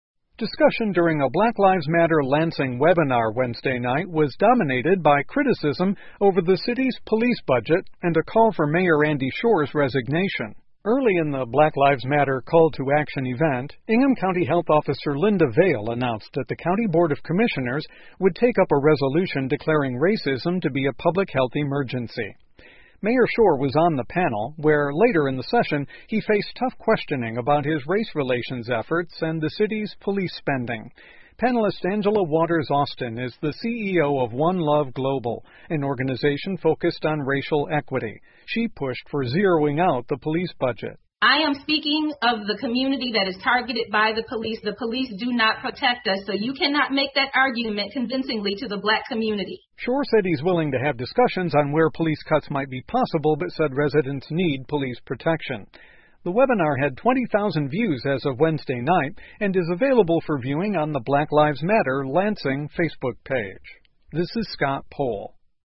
密歇根新闻广播 黑人生活问题 听力文件下载—在线英语听力室